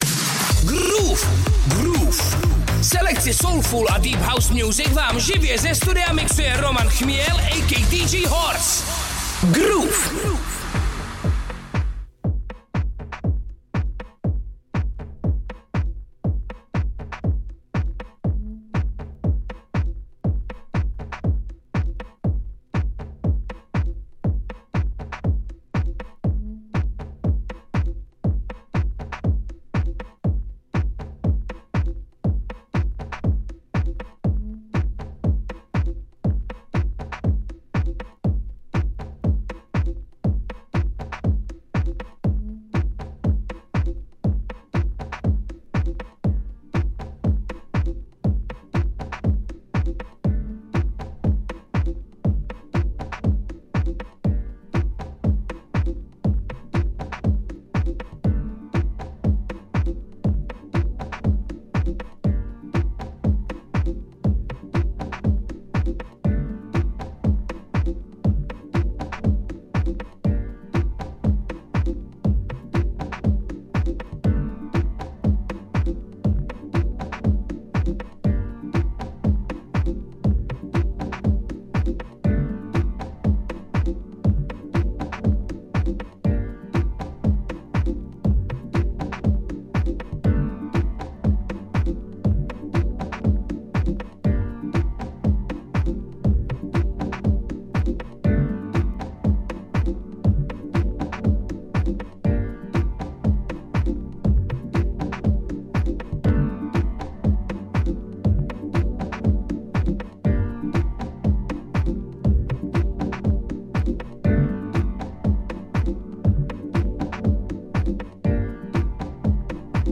deep house music (vinyl set